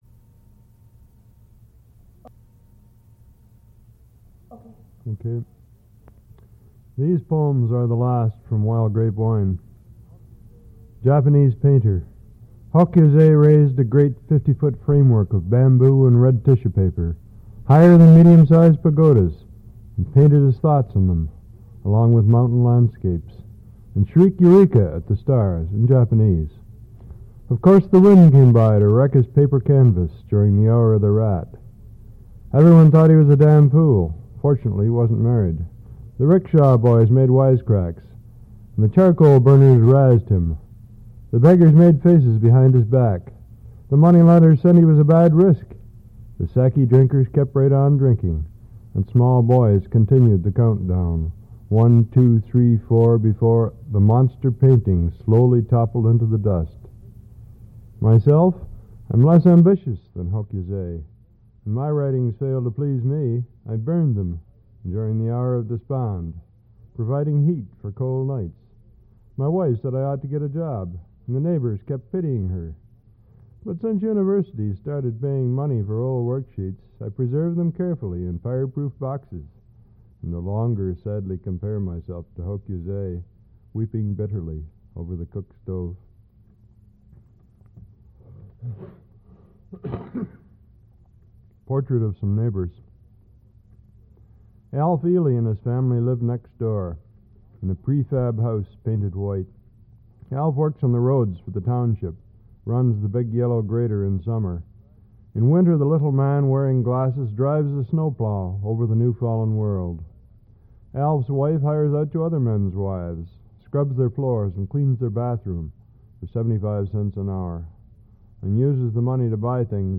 Al Purdy reads his poetry
1/4"' Reel-to-Reel Tape